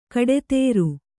♪ kaḍetēru